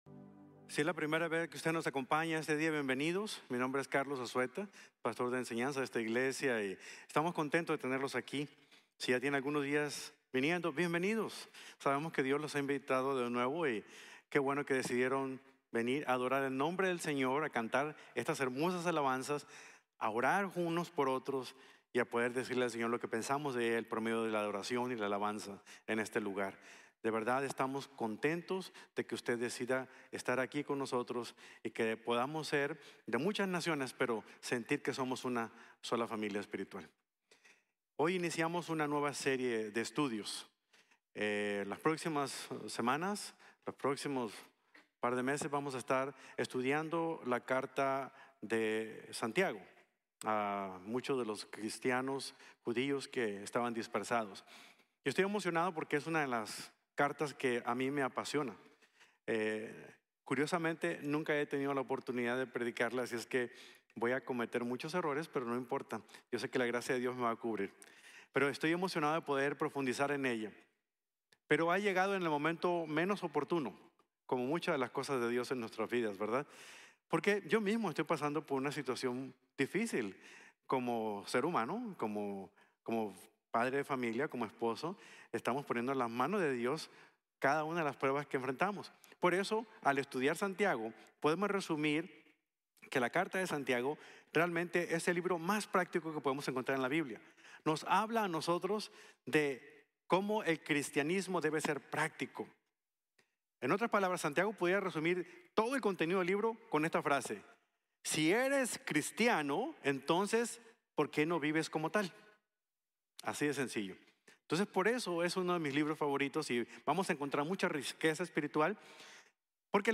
Una de las Herramientas Preferidas de Dios | Sermon | Grace Bible Church